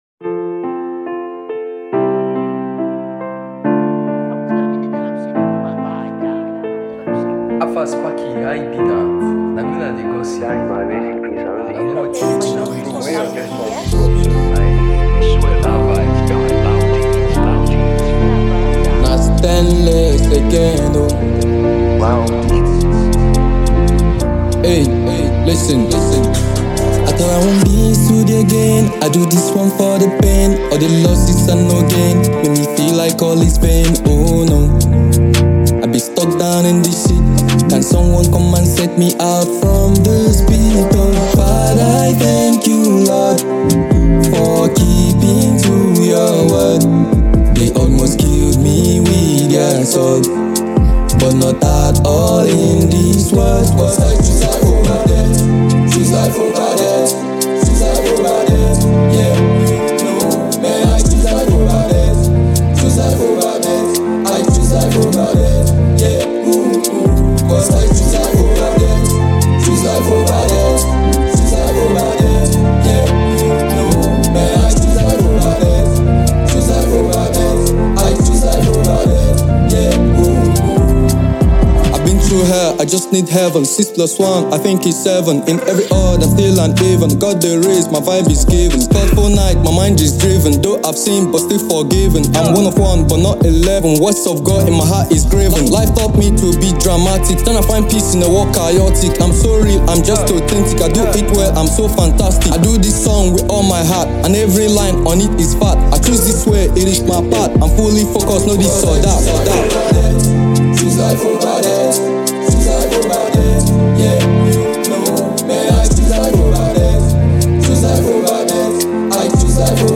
drill song